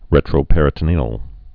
(rĕtrō-pĕrĭ-tn-ēəl)